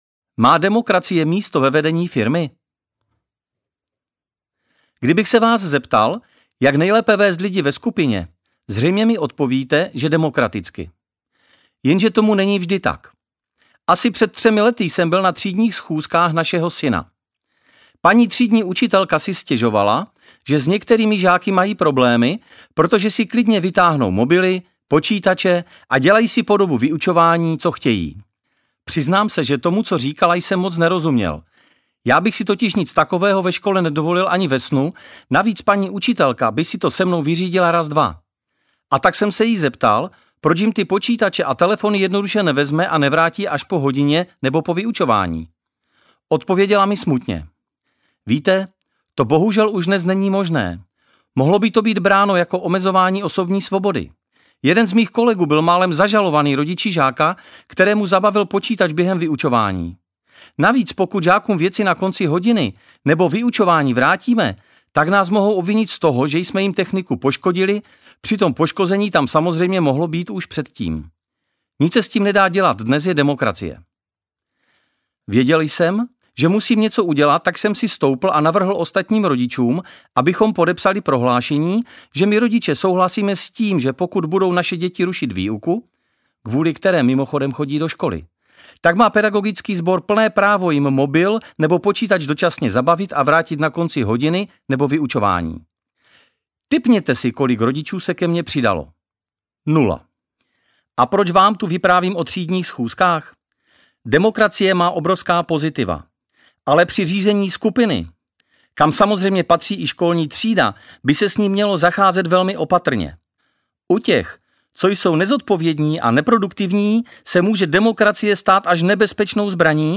Život šéfa audiokniha
Ukázka z knihy